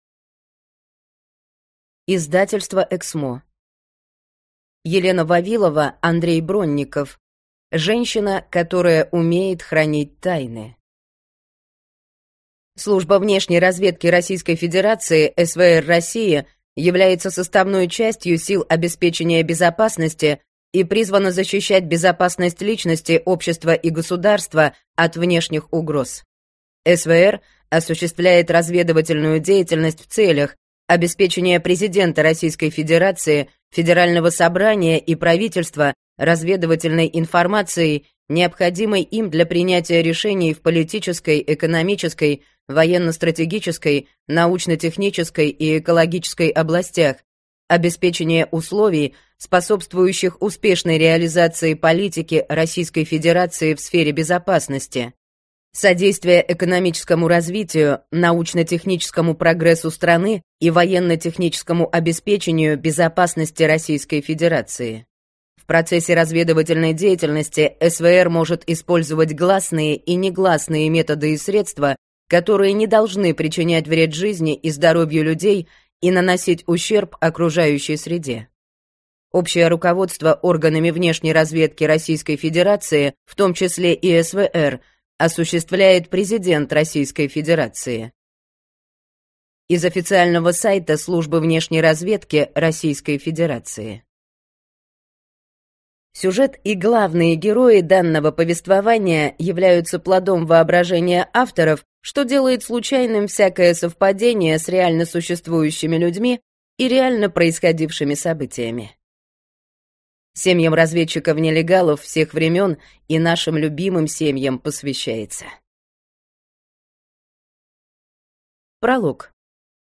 Аудиокнига Женщина, которая умеет хранить тайны | Библиотека аудиокниг